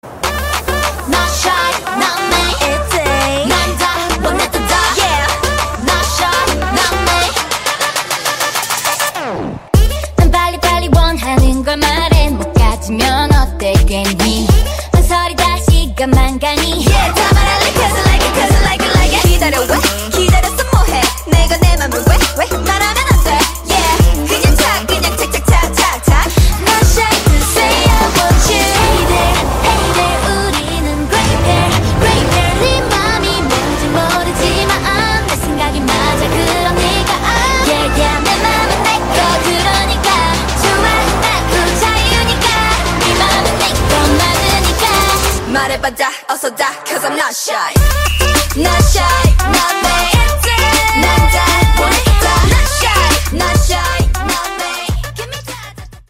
électronique